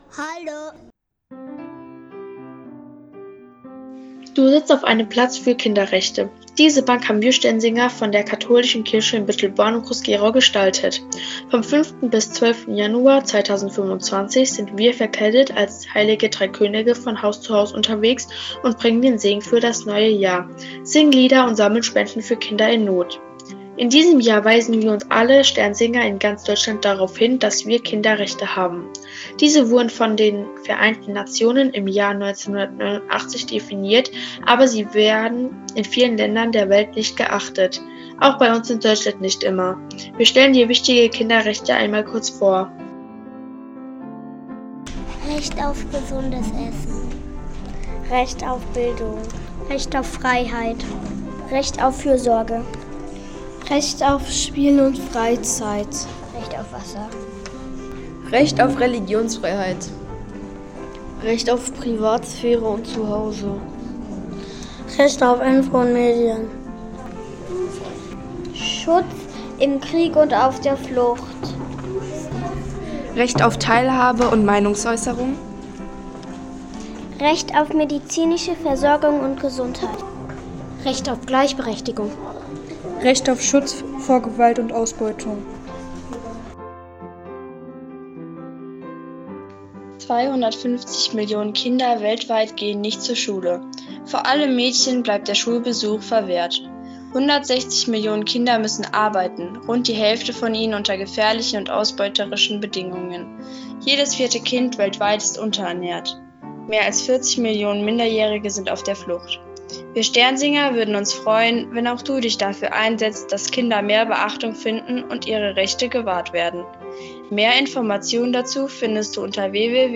Außerdem findet man auf der Bank einen QR-Code, über den man zu einer Aufnahme gelangt, die durch unsere Sternsinger eingesprochen wurde.